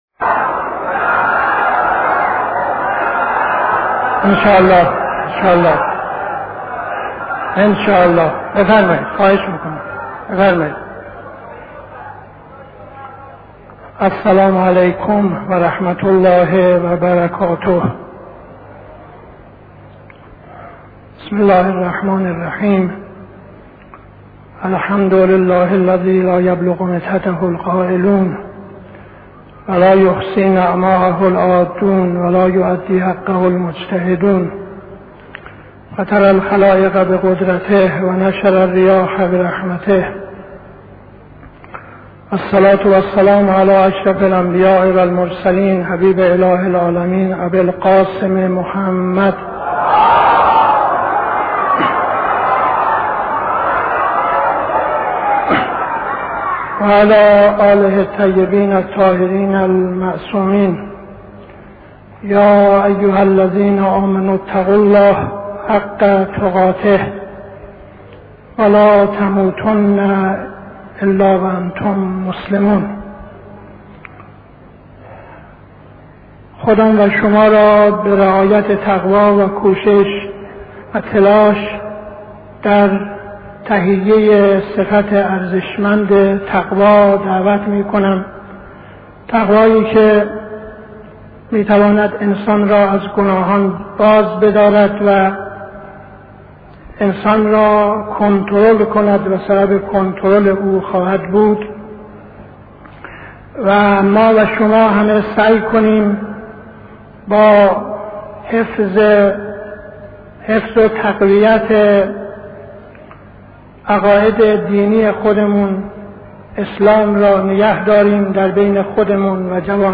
خطبه اول نماز جمعه 26-06-72